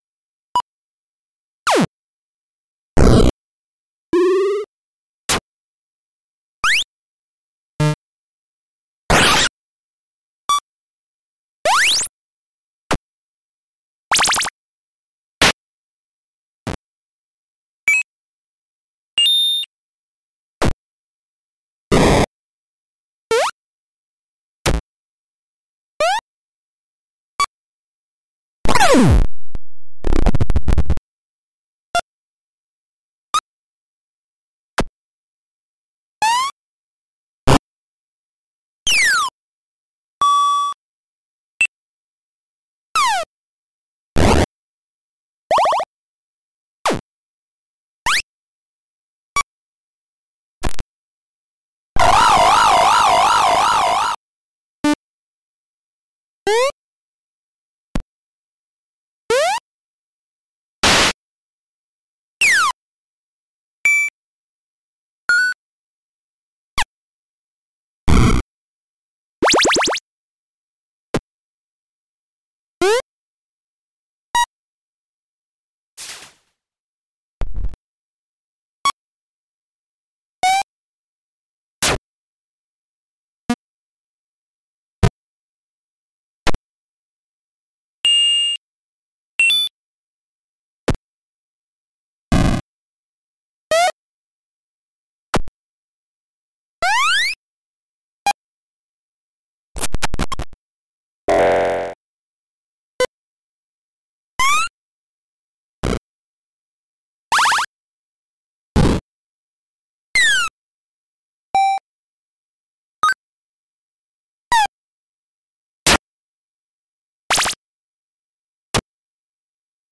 100 Shorts 8-Bit Video Game Sound Effects